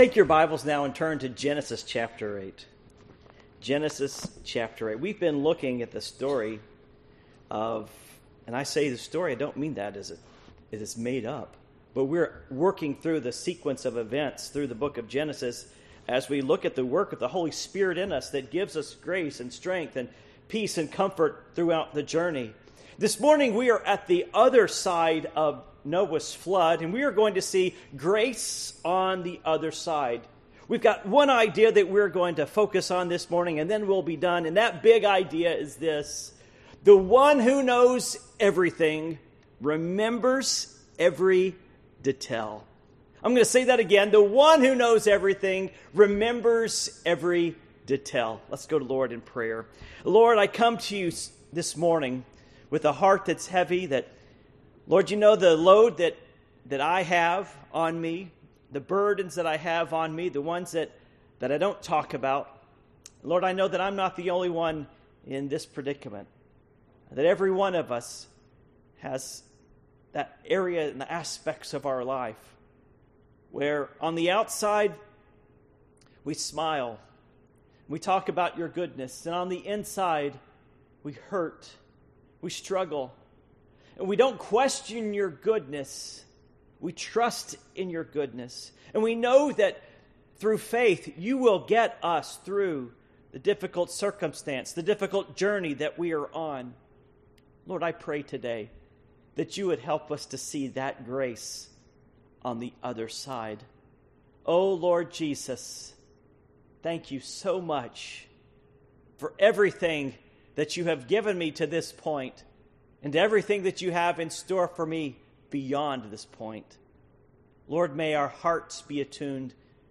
Series: The Ministry of the Encourager Passage: Genesis 8:1-22 Service Type: Morning Worship